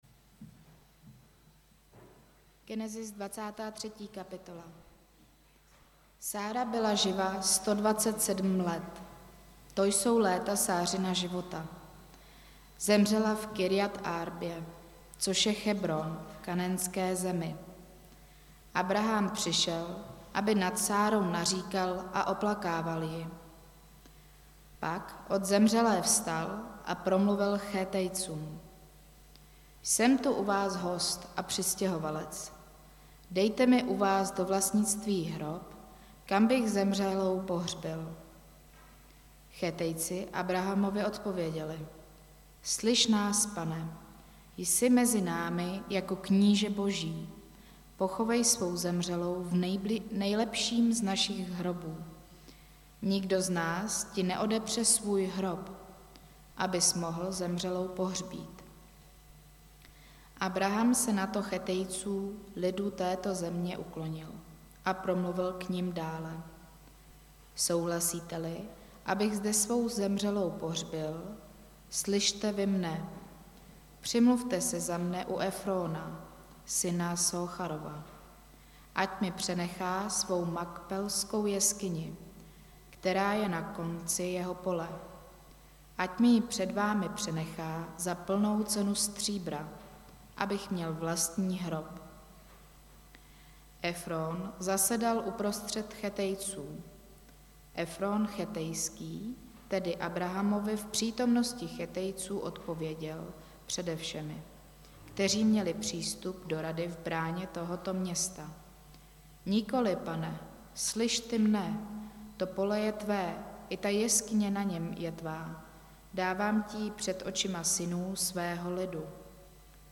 Nedělní kázání – 22.1.2023 Žít pro něco víc – CB Vinohrady